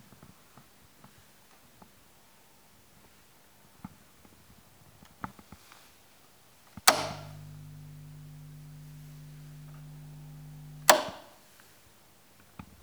Klack 1
Das ist ein Schalter des Sansui AU-777.
Dieser Schalter schaltet wirklich etwas um, mit der ganzen Macht seiner sorgfältig konstruierten Mechanik.
klack-1.wav